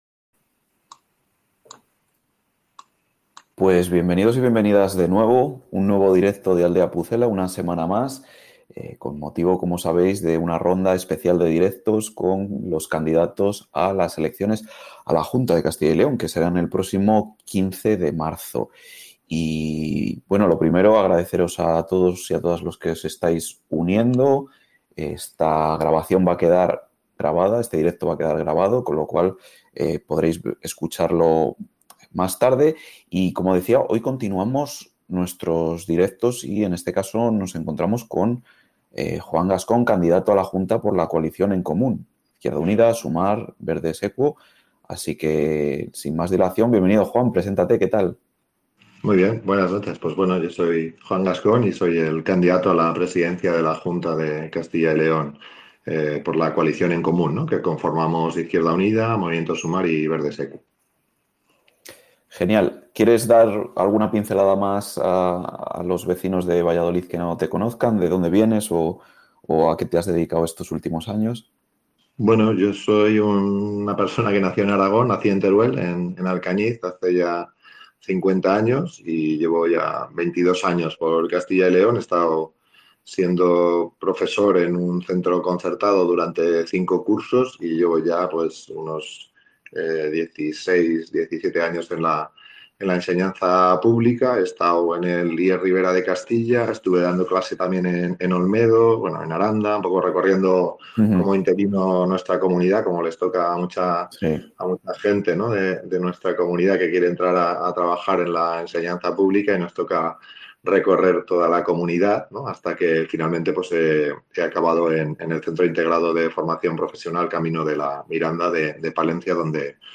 Continuamos nuestros Directos con los candidatos a las elecciones de la Junta 2026 .